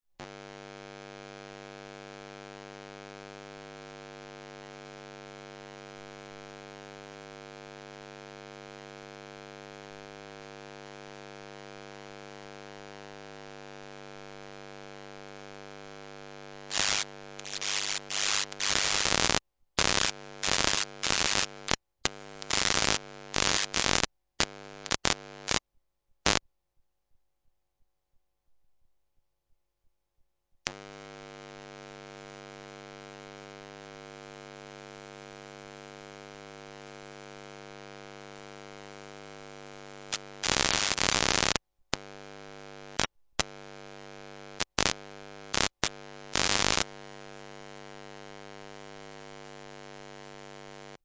电磁场 " 电灯开关
描述：使用Zoom H1和电磁拾音器录制